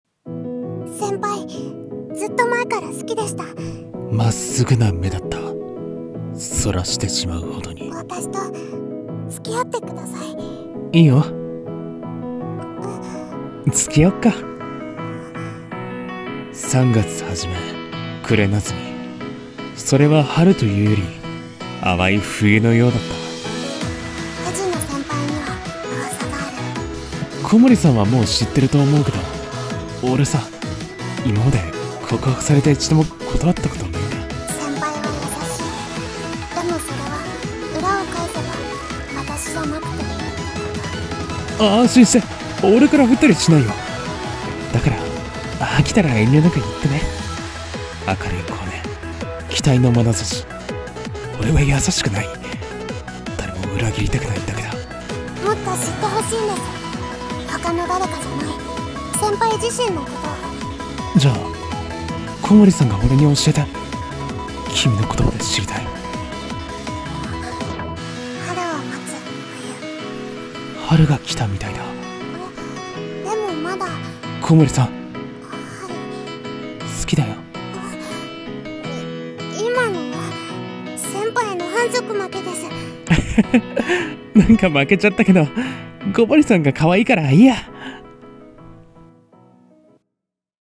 アニメ予告風声劇【春を待つ冬】